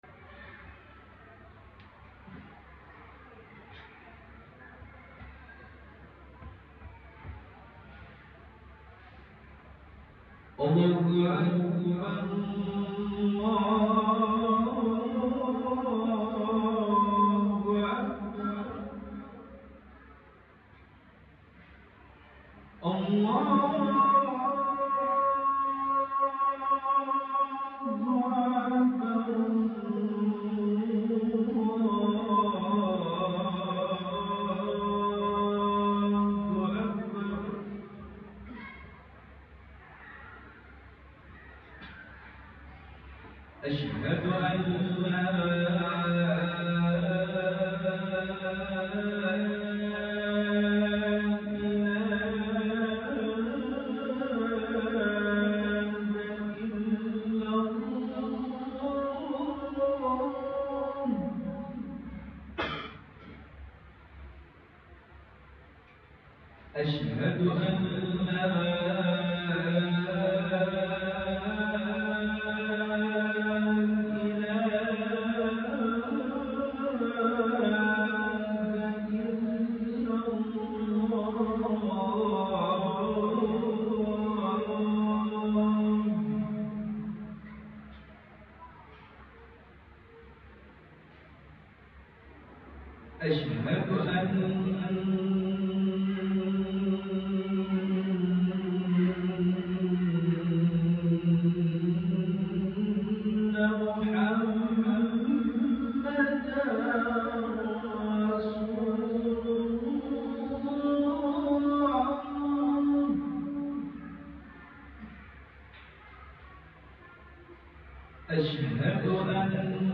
Adzan Hijaz Nhbs (yg Di Sound Effects Free Download